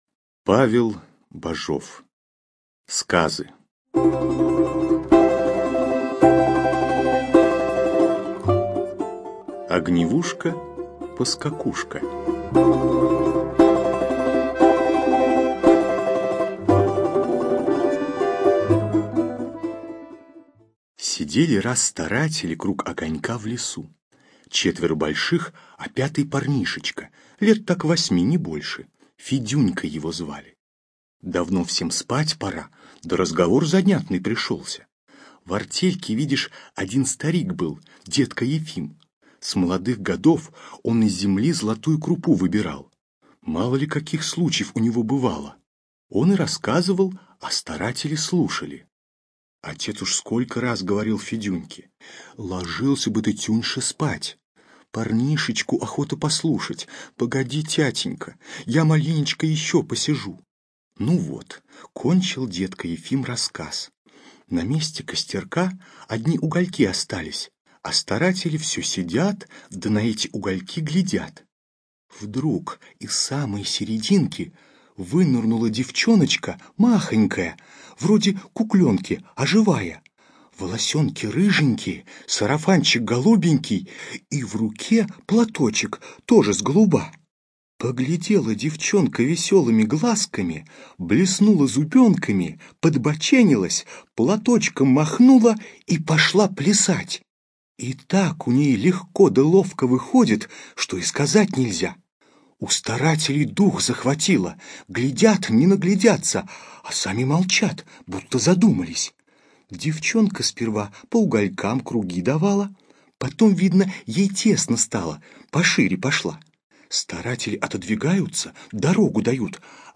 ЖанрДетская литература, Сказки